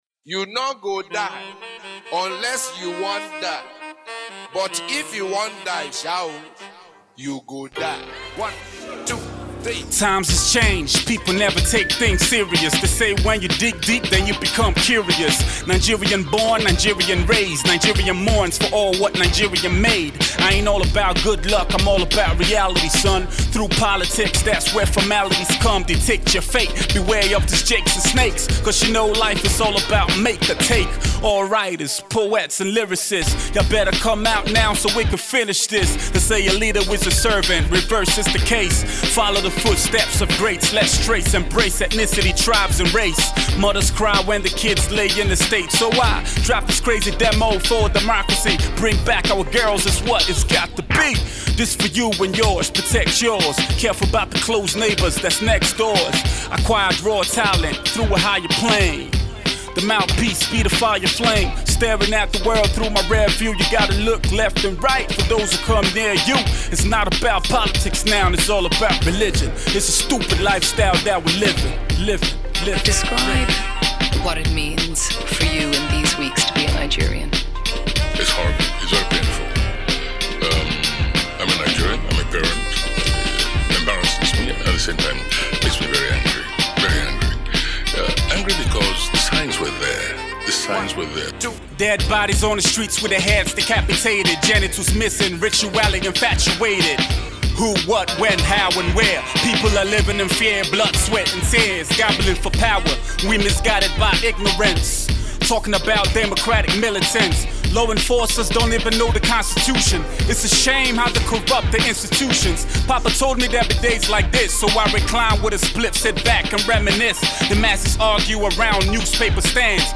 laced a chant of soul on the cut